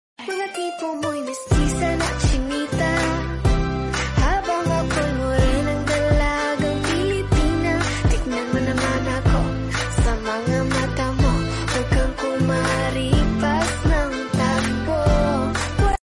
last haha sound effects free download